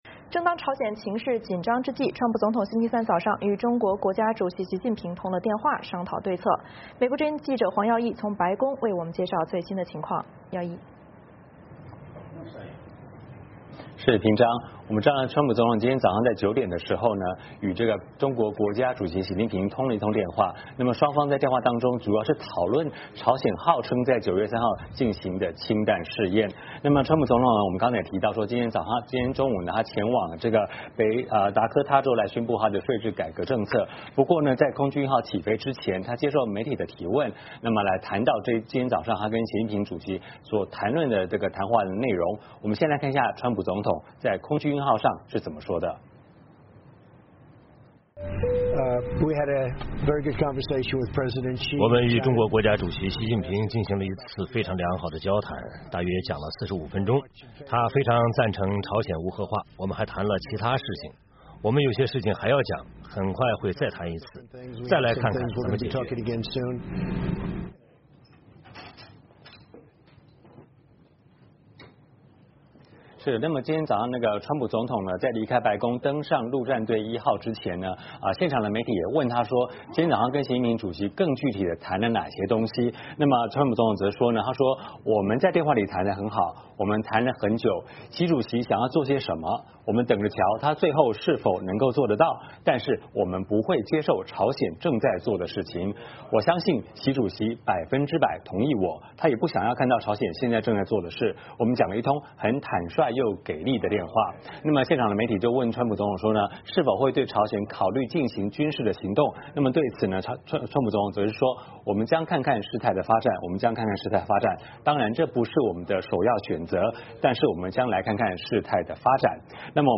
白宫 —